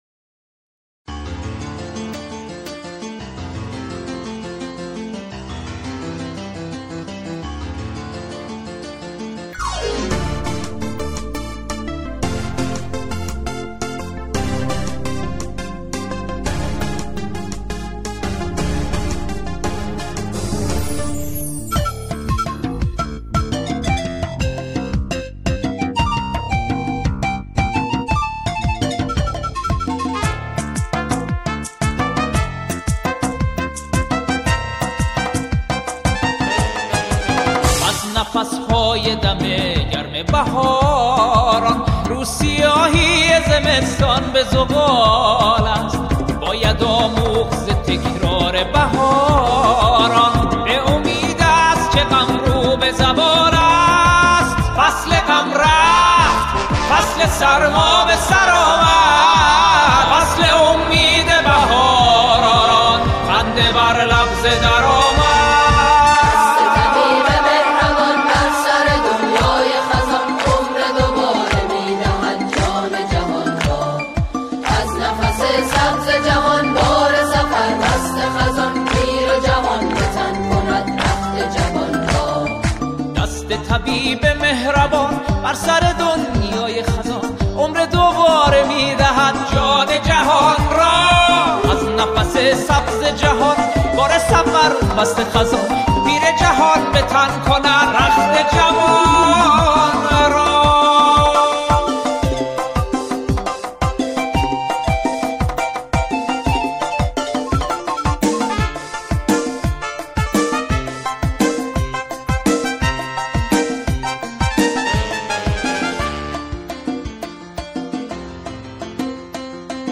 آنها در این قطعه، شعری را درباره نوروز همخوانی می‌کنند.